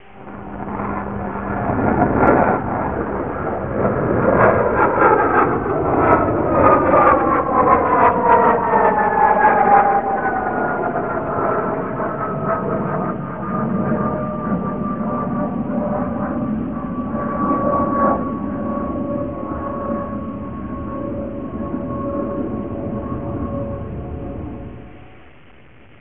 howling.wav